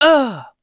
Theo-Vox_Damage_02.wav